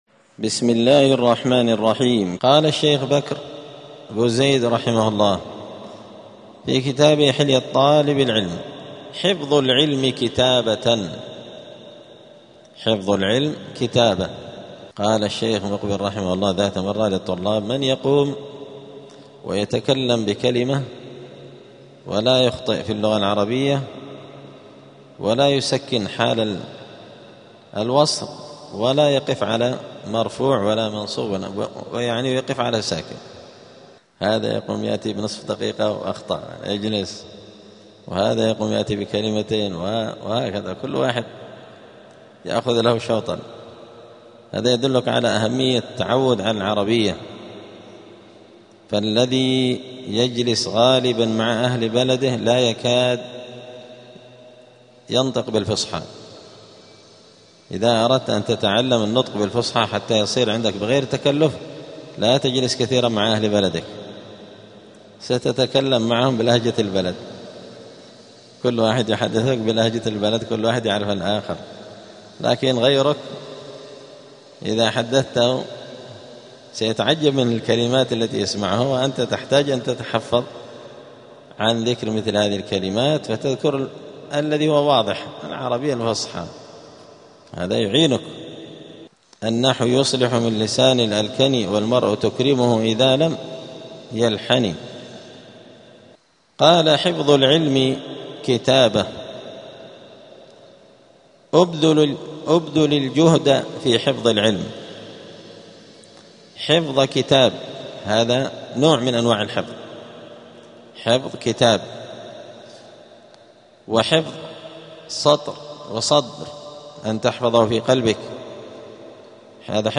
*الدرس السابع والخمسون (57) فصل آداب الطالب في حياته العلمية {حفظ العلم كتابة}.*
الجمعة 7 جمادى الآخرة 1447 هــــ | الدروس، حلية طالب العلم، دروس الآداب | شارك بتعليقك | 7 المشاهدات